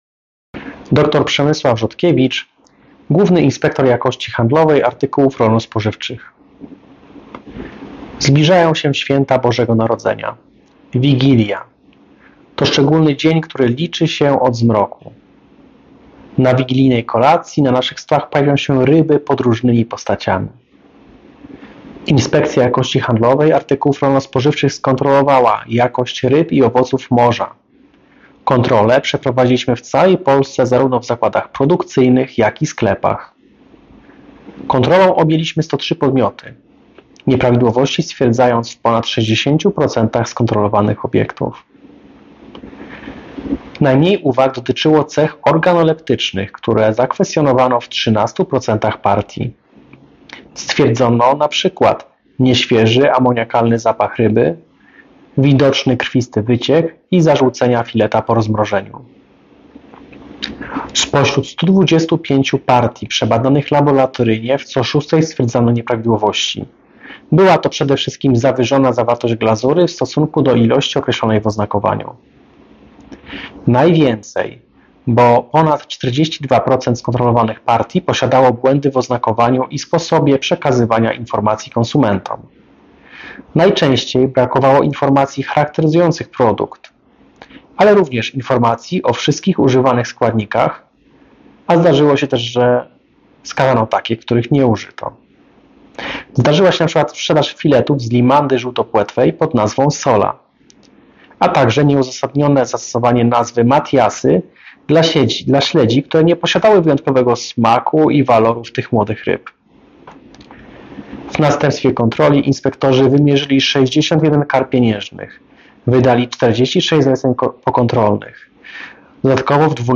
Wypowiedź Przemysława Rzodkiewicza GIJHARS dotycząca kontroli jakości ryb i owoców morza 2024-12-20